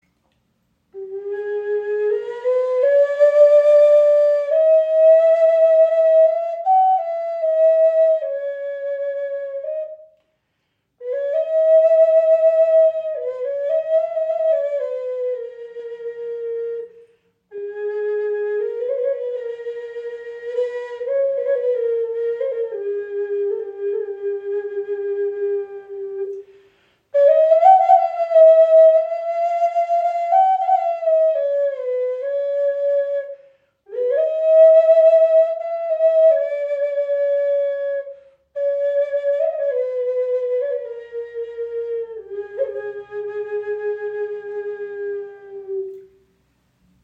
Okarina aus einem Aststück | A4 in 432 Hz | Aeolian Stimmung | ca. 22 cm
Klein, handlich, klangvoll – eine Okarina mit Seele
Handgefertigte 6 Loch Okarina aus Akazienast – klarer, warmer Klang in Aeolischer Stimmung in A (432 Hz), jedes Stück ein Unikat.
Die Okarina spricht besonders klar an und erklingt in der Aeolischen Stimmung in A4, fein abgestimmt auf 432 Hz – ein Ton, der Herz und Geist in harmonische Schwingung versetzt.
Trotz ihrer handlichen Grösse erzeugt sie einen angenehm tiefen, warmen Klang – fast ebenbürtig zur nordamerikanischen Gebetsflöte.